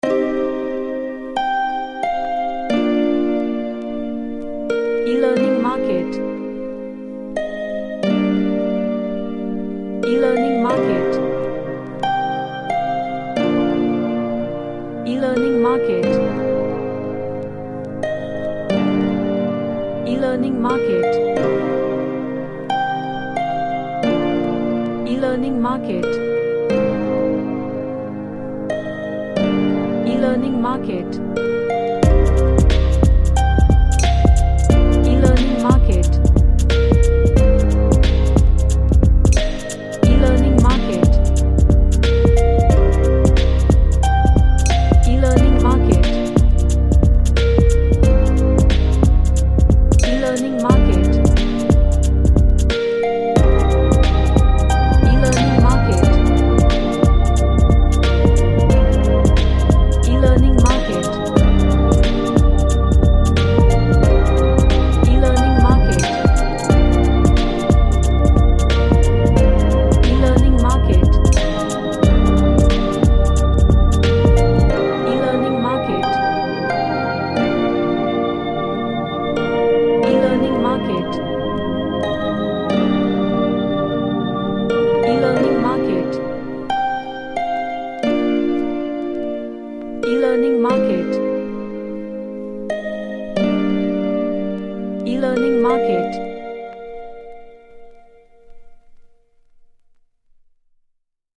A chill track featuring Harp
Gentle / Light